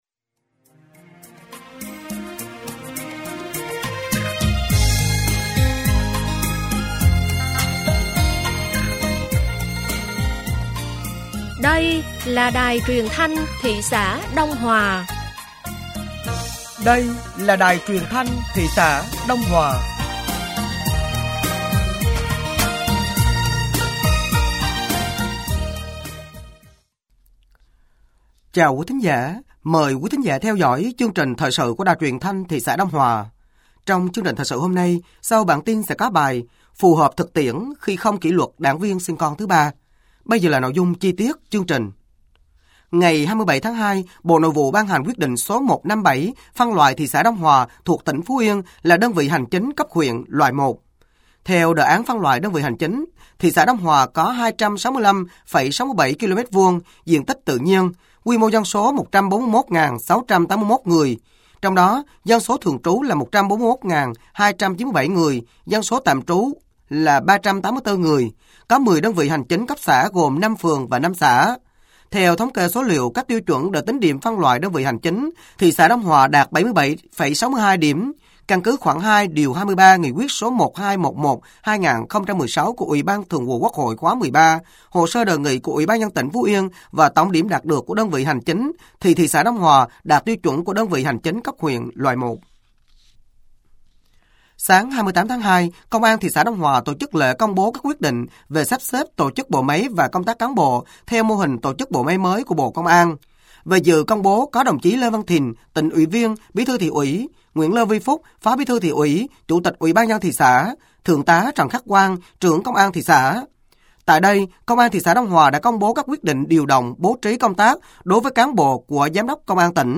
Thời sự tối ngày 28 và sáng ngày 01 tháng 03 năm 2025